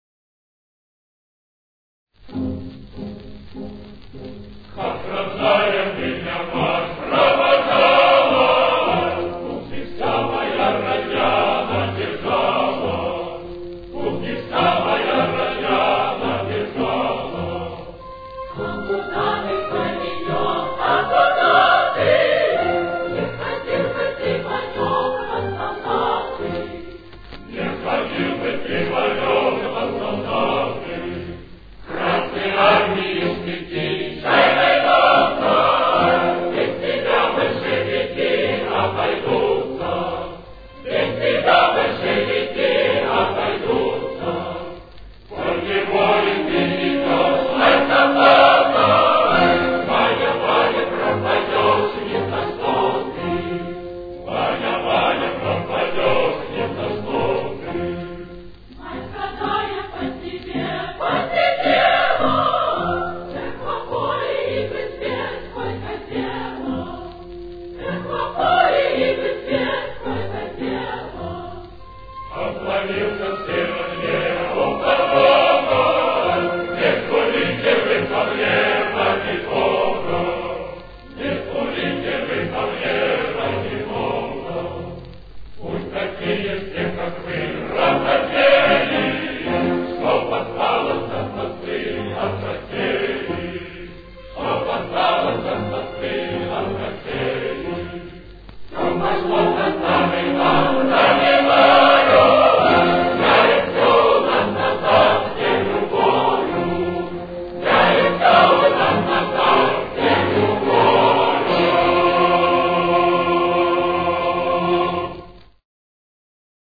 с очень низким качеством (16 – 32 кБит/с)
Фа минор. Темп: 108.